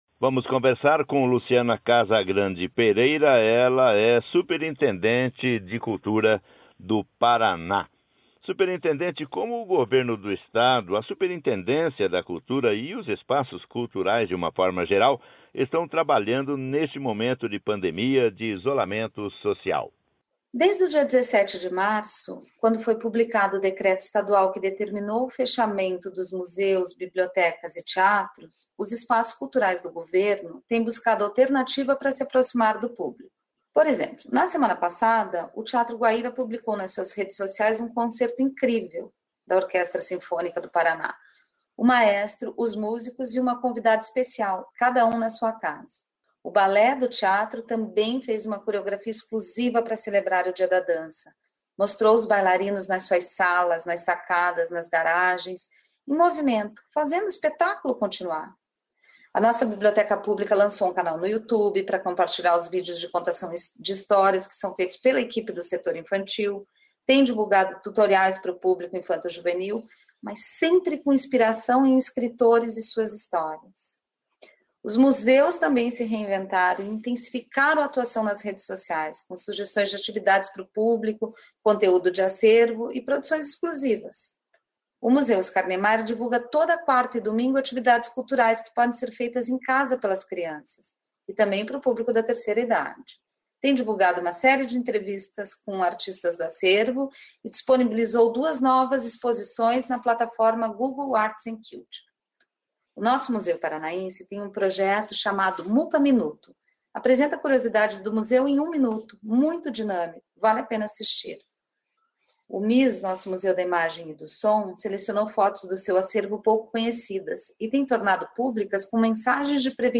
ENTREVISTA COM A SUPERINTENDENTE DE CULTURA DO PARANÁ, LUCIANA CASAGRANDE PEREIRA
ENTREVISTA COM A SUPERINTENDENTE DE CULTURA DO PARANÁ, LUCIANA CASAGRANDE PEREIRA 06/05/2020 ENTREVISTA COM A SUPERINTENDENTE DE CULTURA DO PARANÁ, LUCIANA CASAGRANDE PEREIRA, SOBRE O LANÇAMENTO PELA SECRETARIA DE ESTADO DA COMUNICAÇÃO SOCIAL E DE CULTURA, DO PACOTE DE MEDIDAS DE APOIO E FORTALECIMENTO DO SETOR CULTURAL.